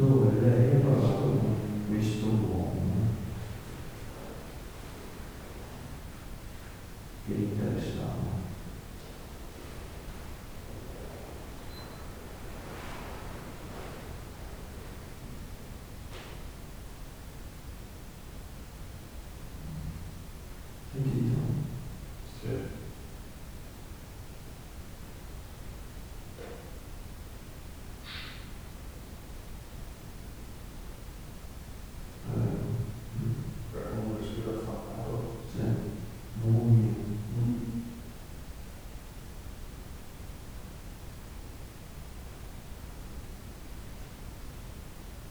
Campione registrato in sala pietra, si sente lo sviluppo di un possibile mugugno .
campione originale possibile mugugno.wav